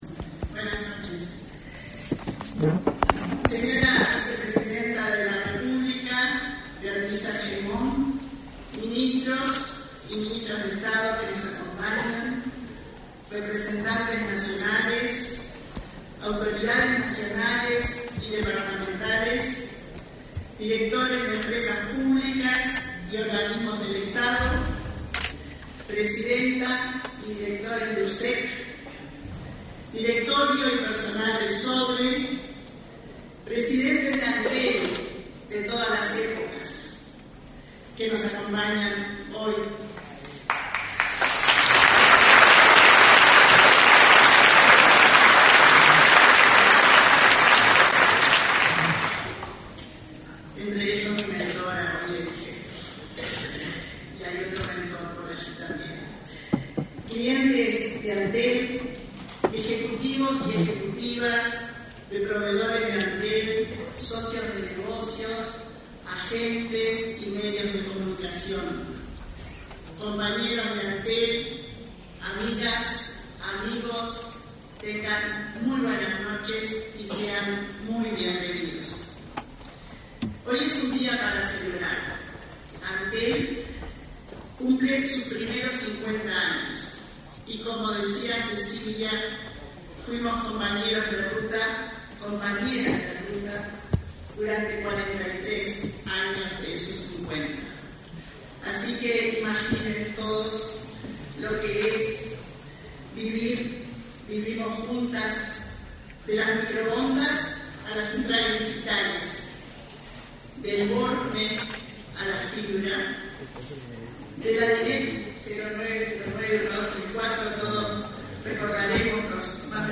Palabras de la presidenta de Antel, Annabela Suburú
Antel celebró, este 26 de julio, el 50.°aniversario de su creación. En el acto participó la presidenta del ente, Annabela Suburú.